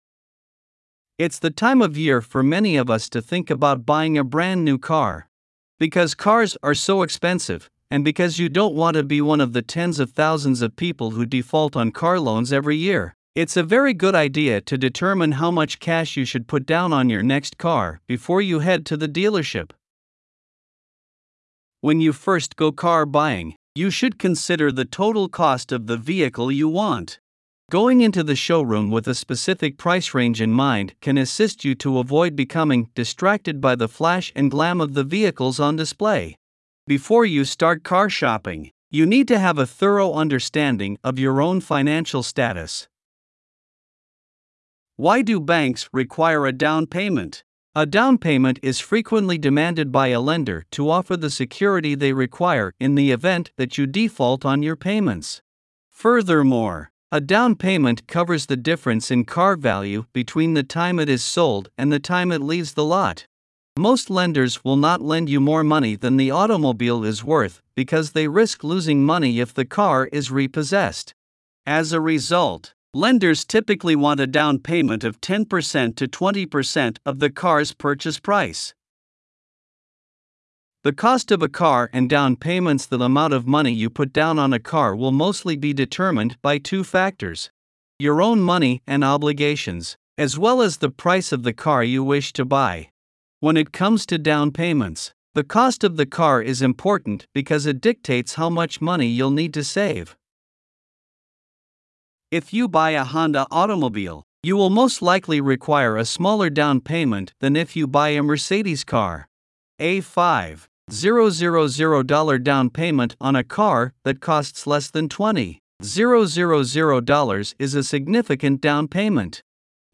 Voiceovers-Voices-by-Listnr_9.mp3